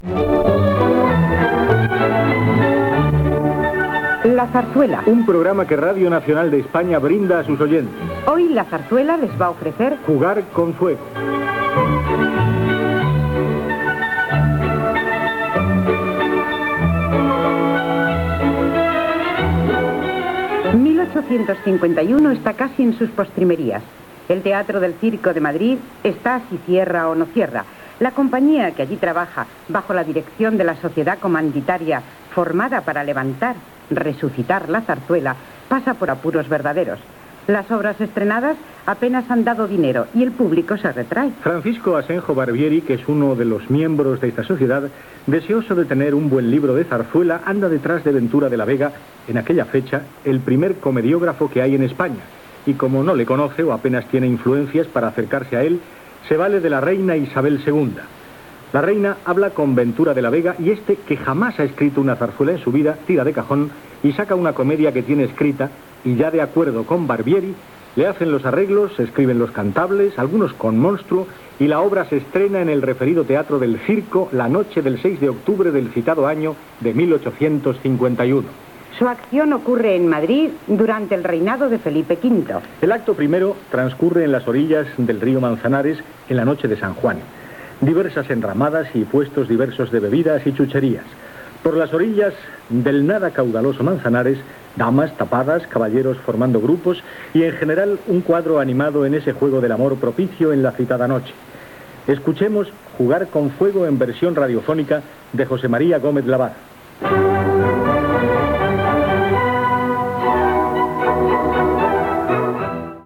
Careta del programa, espai dedicat a la sarsuela "Jugar con fuego". Explicació de com es va composar i sel seu argument
Musical